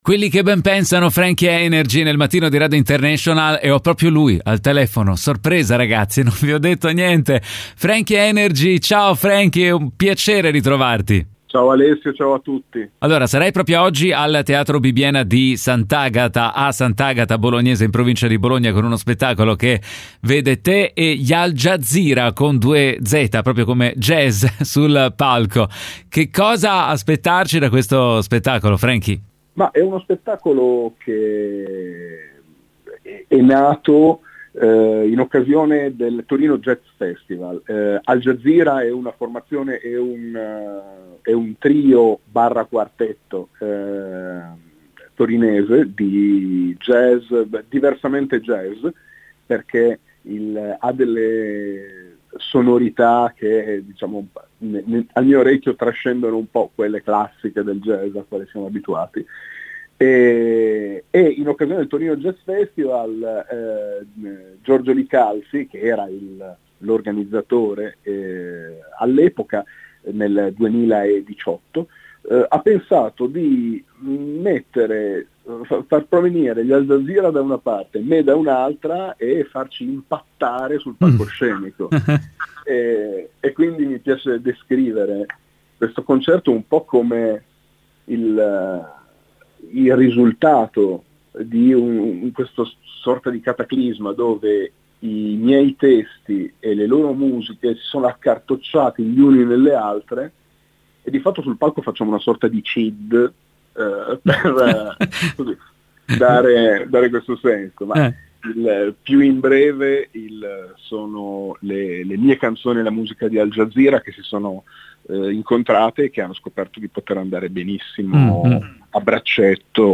intervista a Frankie HI-NRG MC